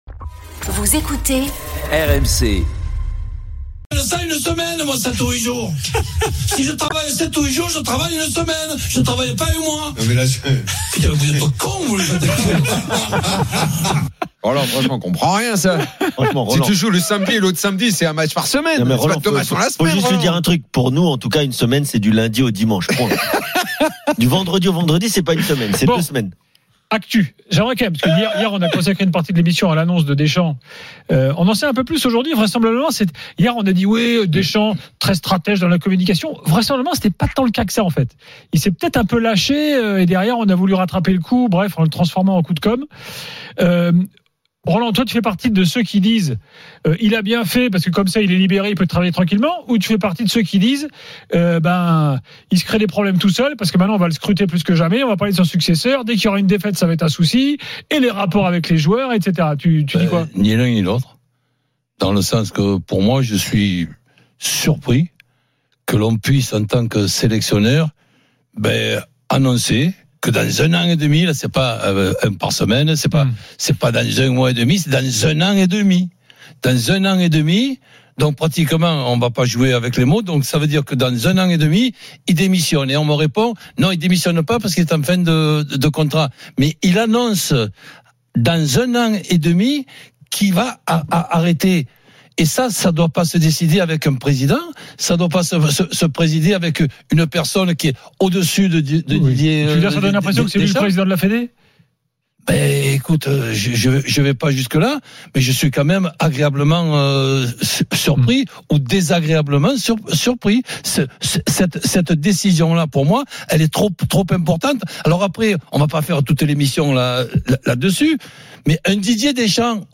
L’After foot, c’est LE show d’après-match et surtout la référence des fans de football depuis 19 ans !
avec les réactions des joueurs et entraîneurs, les conférences de presse d’après-match et les débats animés entre supporters, experts de l’After et auditeurs RMC.